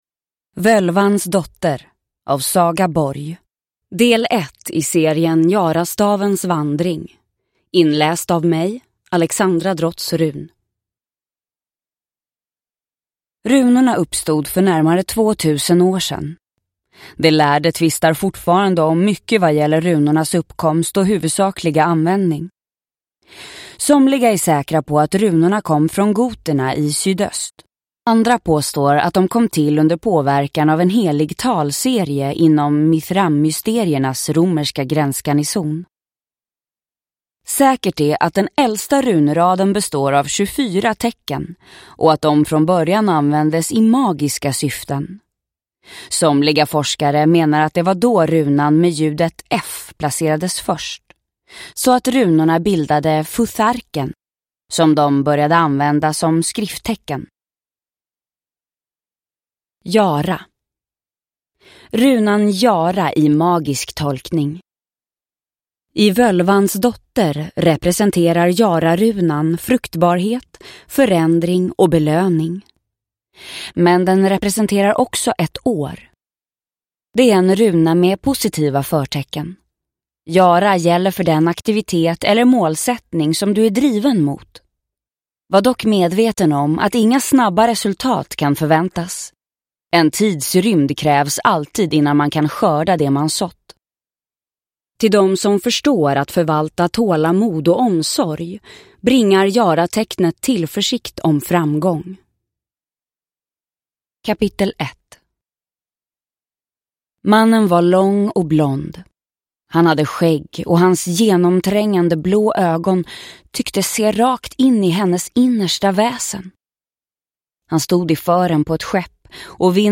Völvans dotter – Ljudbok – Laddas ner